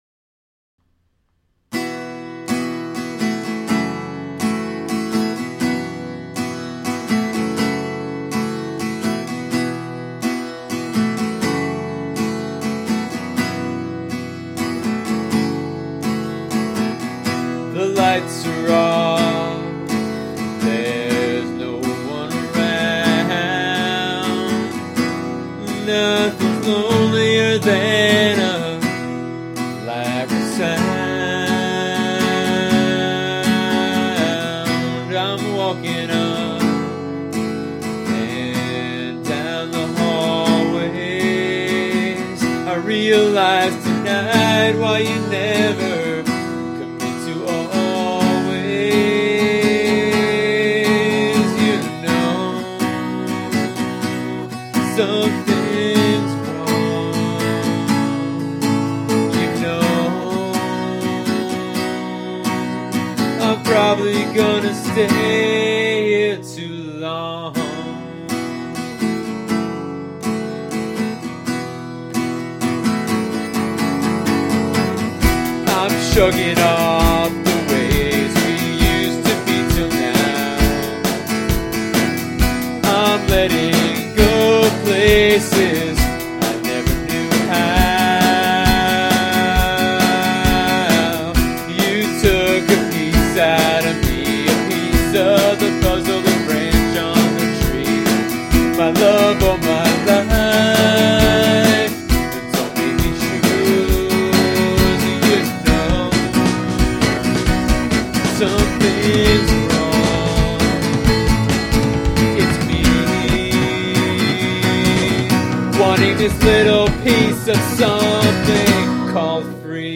Later, upon playback, the acoustic guitar tracks were buzzing and sounded generally sloppy.
For now, though, have a listen to the early, incomplete mix and imagine what it will be like when it is complete.
“No Lights, No Sound” (Rough)